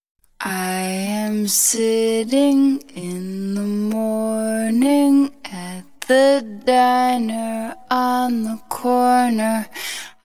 vocals-20-el.wav